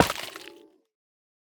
Minecraft Version Minecraft Version 1.21.5 Latest Release | Latest Snapshot 1.21.5 / assets / minecraft / sounds / block / sculk_catalyst / break2.ogg Compare With Compare With Latest Release | Latest Snapshot
break2.ogg